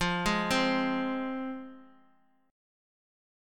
Fm Chord
Listen to Fm strummed